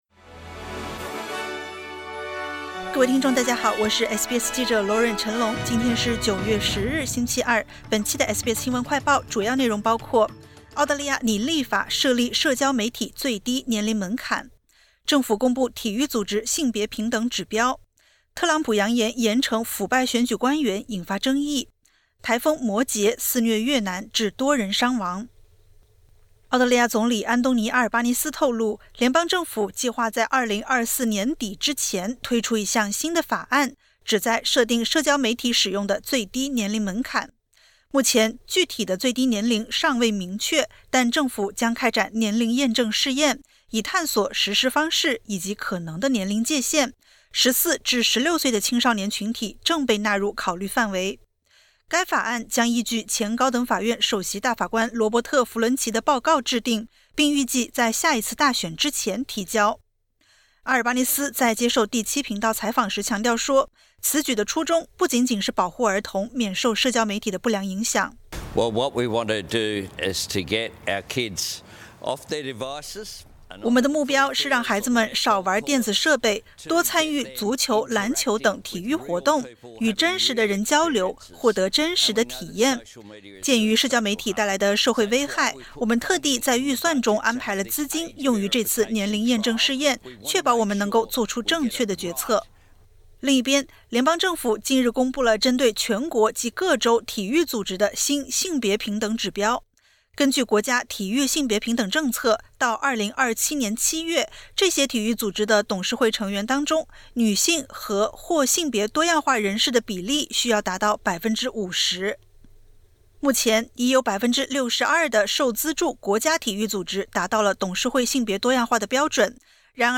【SBS新闻快报】澳大利亚拟立法设社交媒体最低年龄门槛